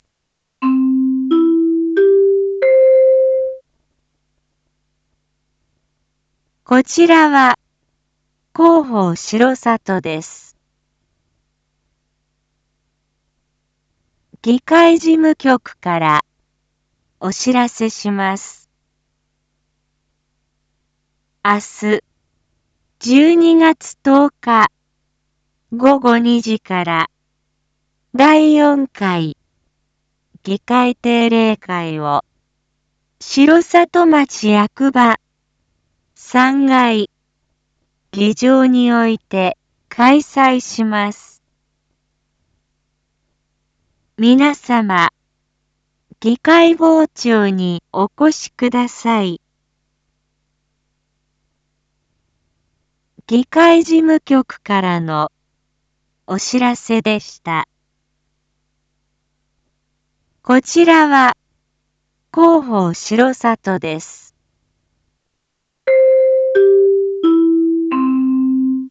一般放送情報
Back Home 一般放送情報 音声放送 再生 一般放送情報 登録日時：2024-12-09 19:01:11 タイトル：第４回議会定例会⑤ インフォメーション：こちらは広報しろさとです。